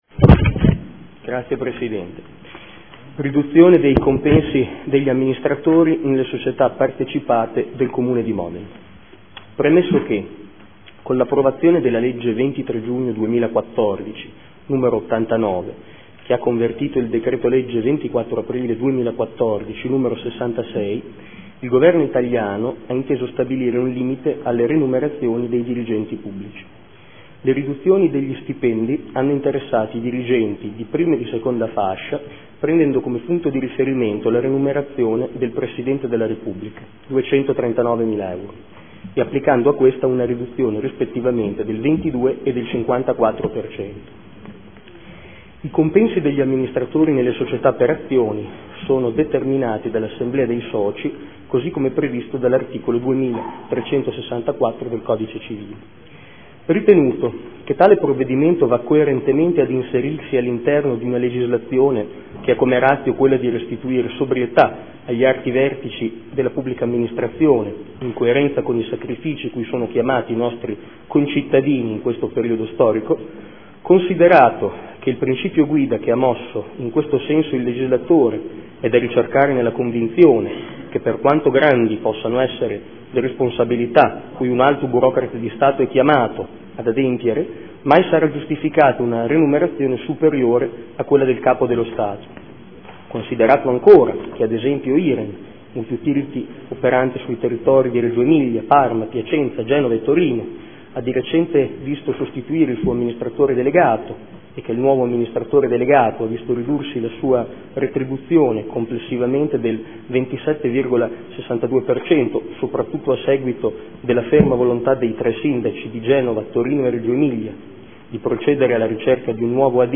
Marco Forghieri — Sito Audio Consiglio Comunale